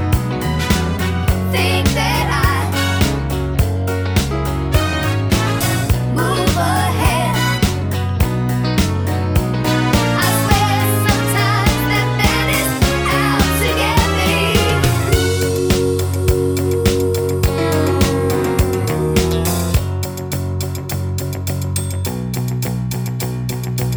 no Backing Vocals Country (Female) 2:45 Buy £1.50